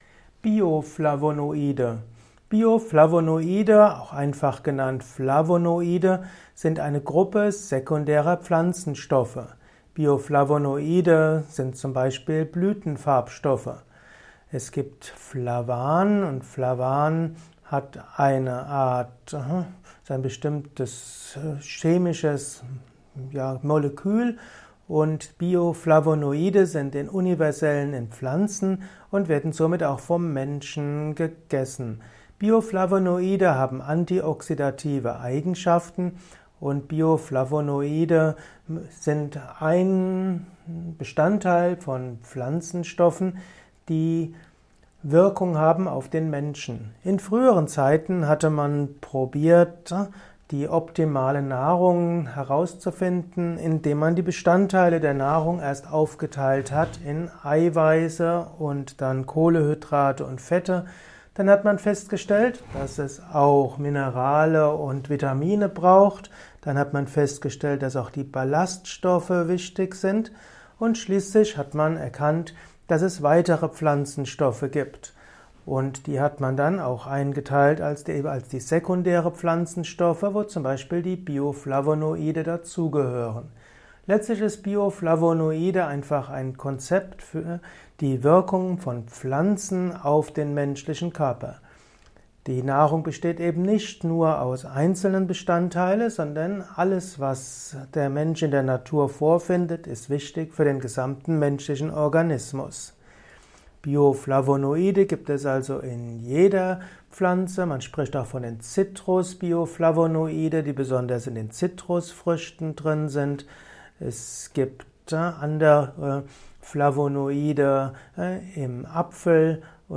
Kompakte Informationen zum Thema Bioflavonoide in diesem Kurzvortrag